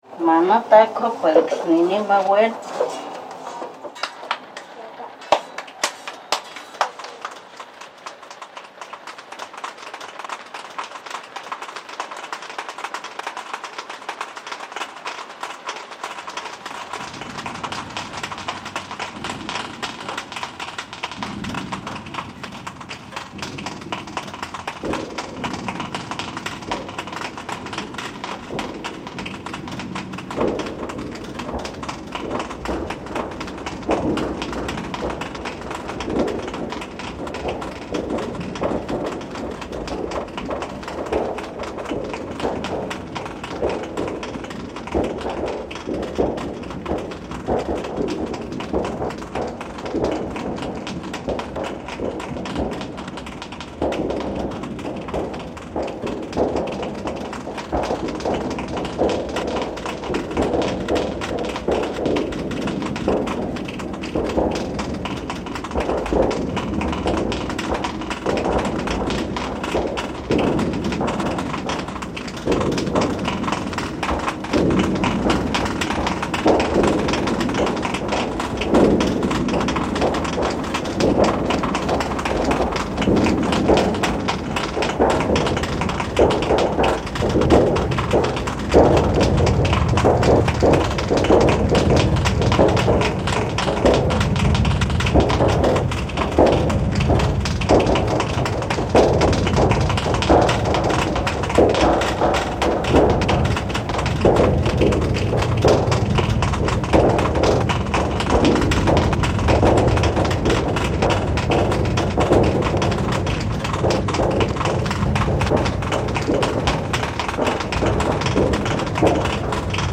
Mexican tortilla making reimagined